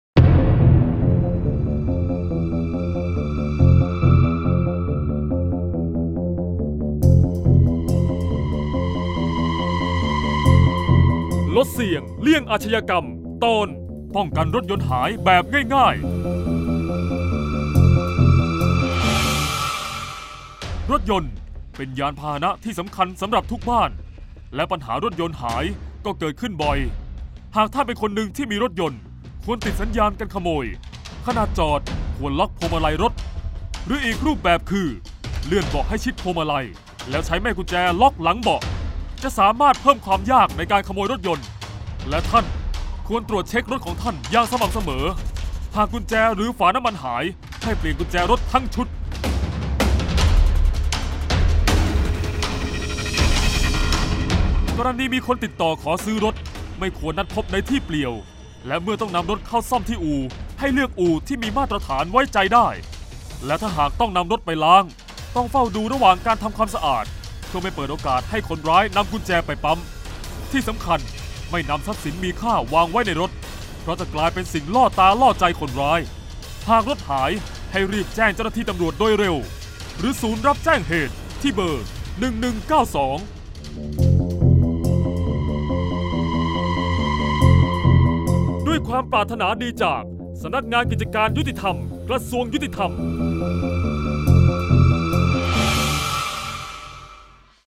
เสียงบรรยาย ลดเสี่ยงเลี่ยงอาชญากรรม 05-ป้องกันรถยนต์หาย
ลักษณะของสื่อ :   คลิปเสียง, กฎหมาย ระเบียบ, คลิปการเรียนรู้